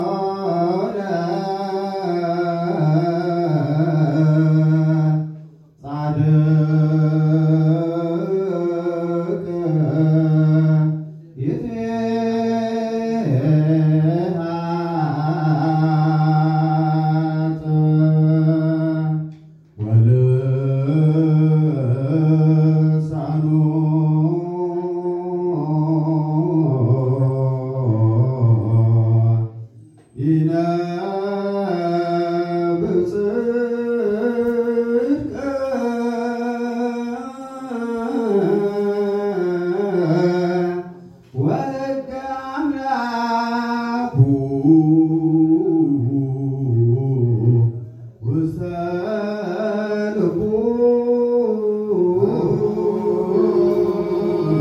ታህሳስ-፳፬-ተክለ-ሃይማኖት-ምስባክ-አፉሁ-ለጻድቅ-ይትሜሀር-ጥበበ.mp3